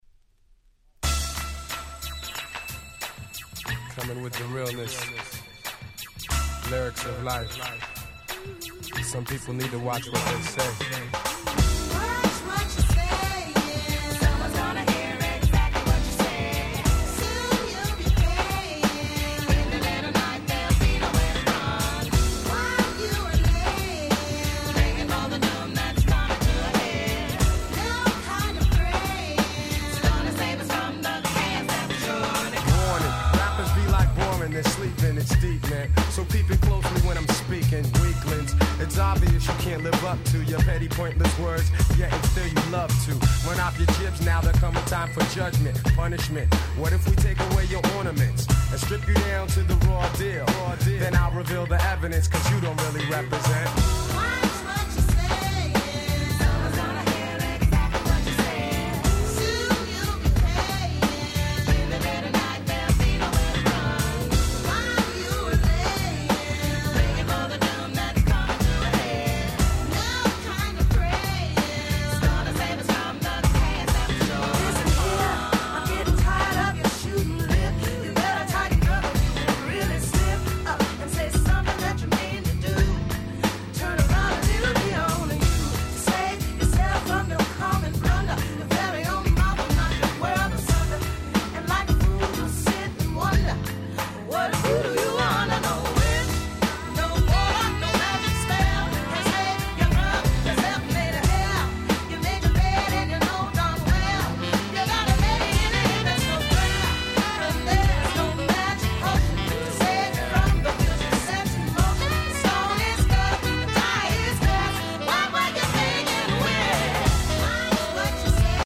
95' Smash Hit Hip Hop !!